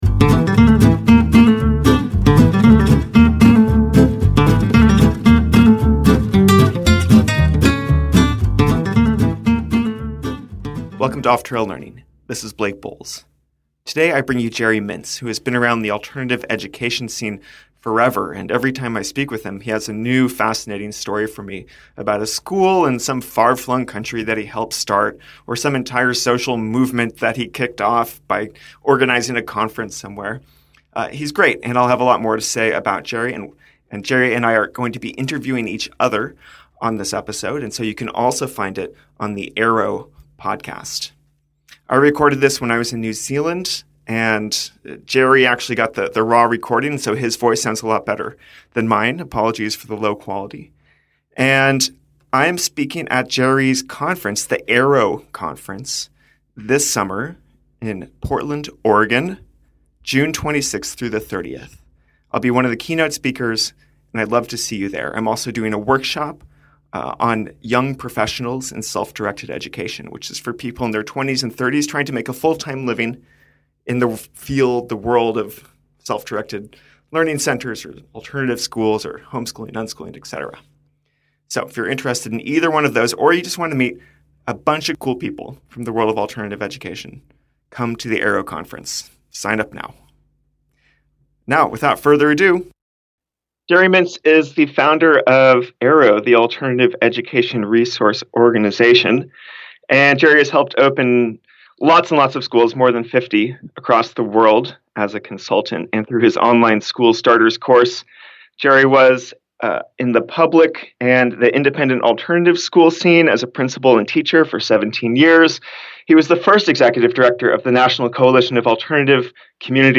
interview each other about the state of alternative education in the world